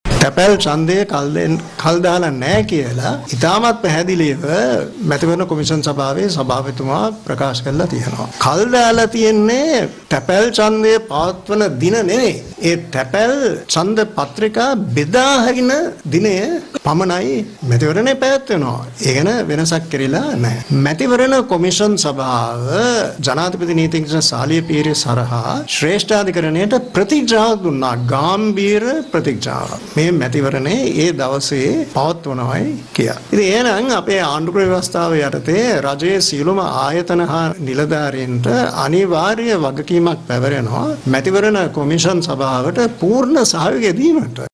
ඔහු මෙම අදහස් පල කළේ අද කොළඹ පැවති මාධ්‍ය හමුවකට එක්වෙමින්.